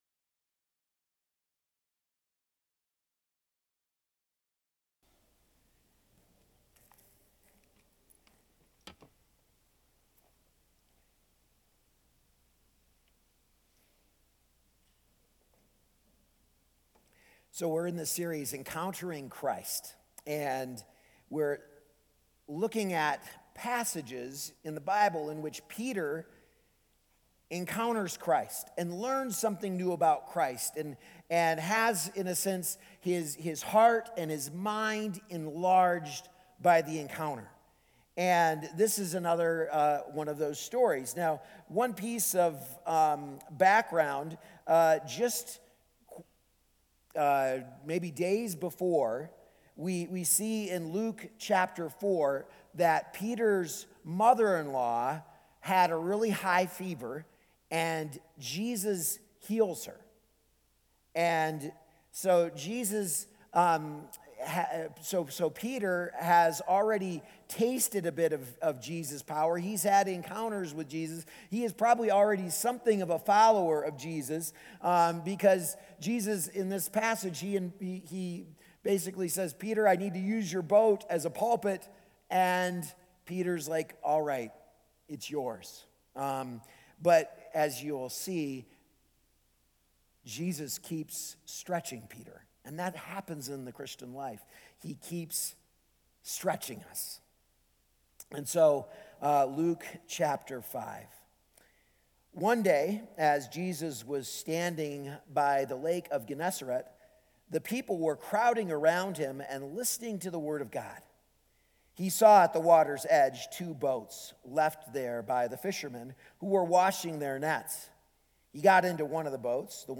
A message from the series "Encountering Christ."